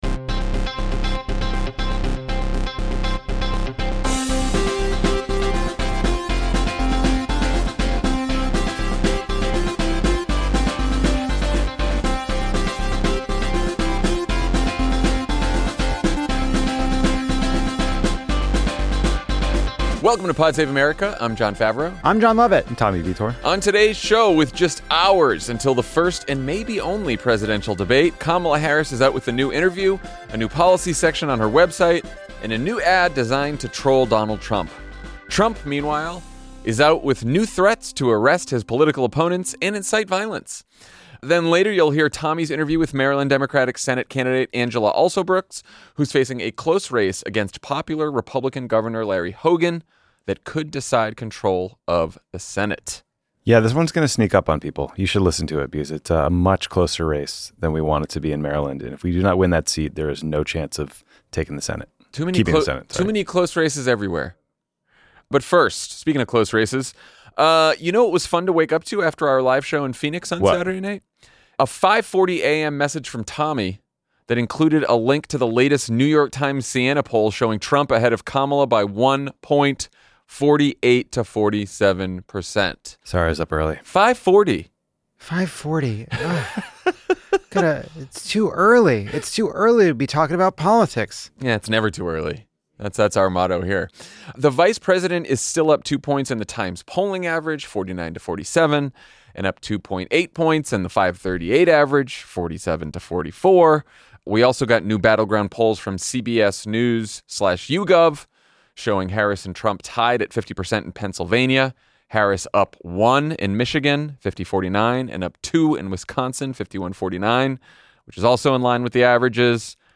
Then, Senate candidate Angela Alsobrooks joins Tommy in studio to talk about why people need to pay close attention to the Maryland Se